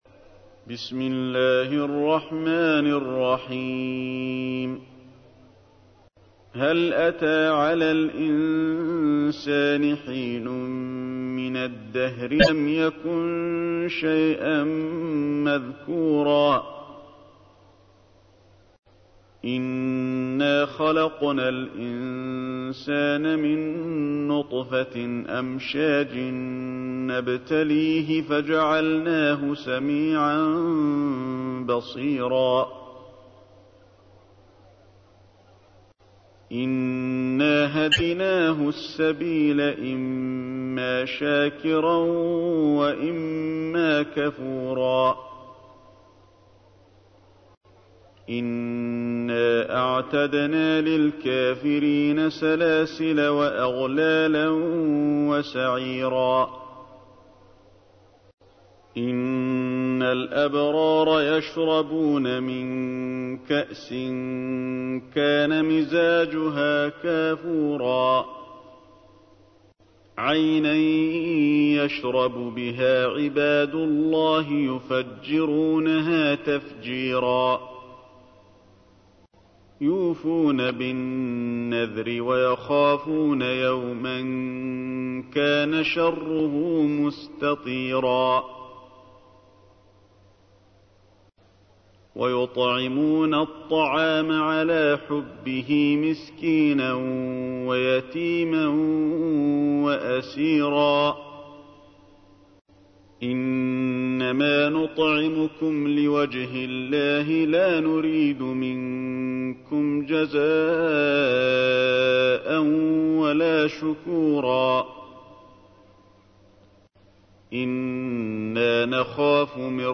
تحميل : 76. سورة الإنسان / القارئ علي الحذيفي / القرآن الكريم / موقع يا حسين